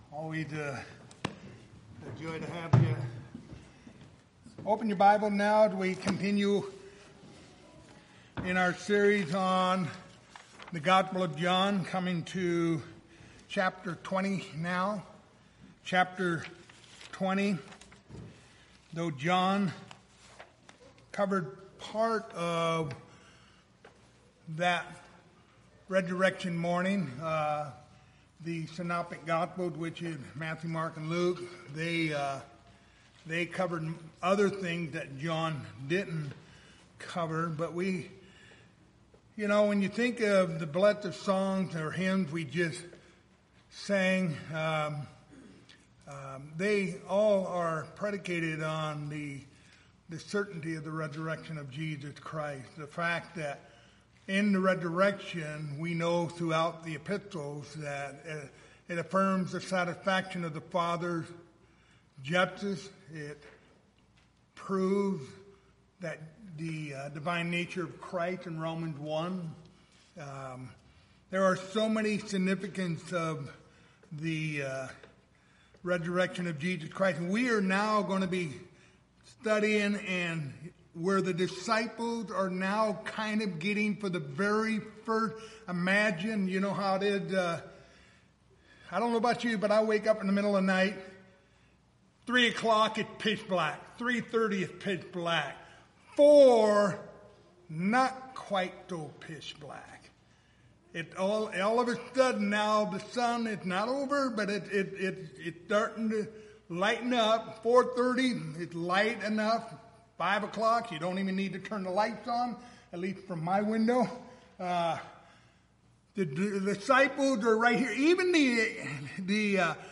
The Gospel of John Passage: John 20:1-10 Service Type: Wednesday Evening Topics